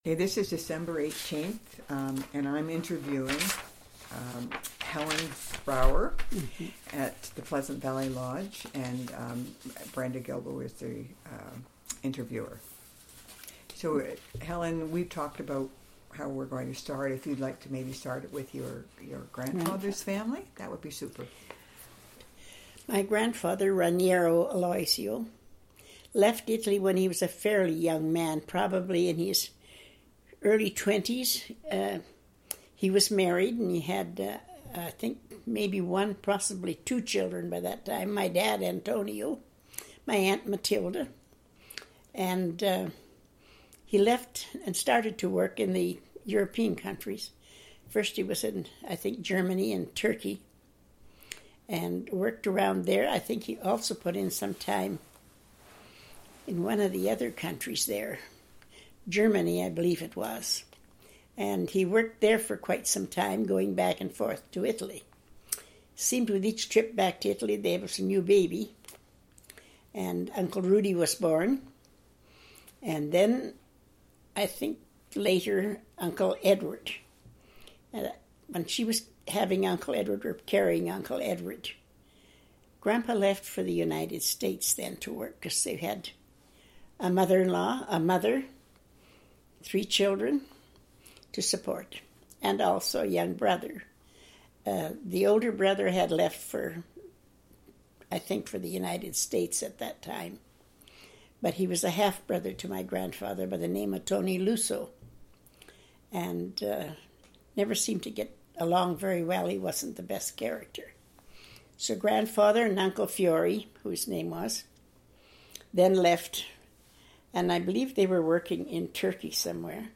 Audio, Transcript of audio interview and Obituary,